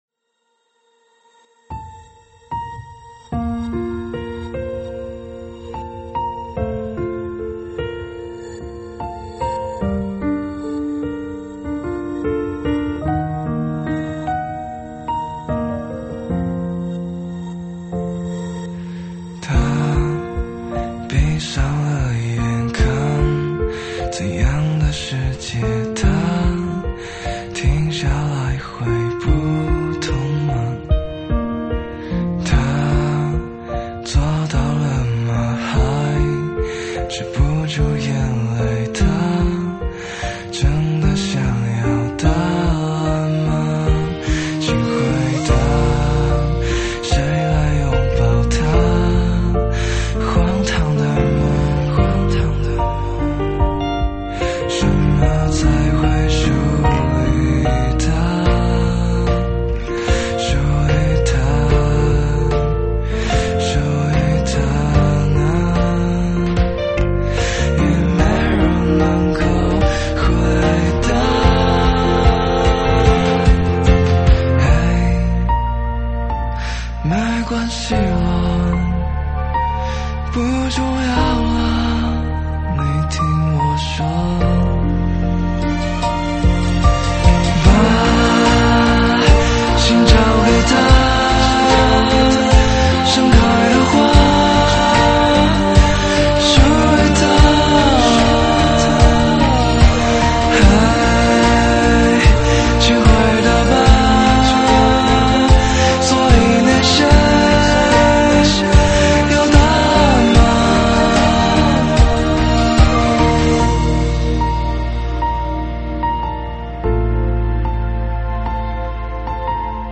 中文舞曲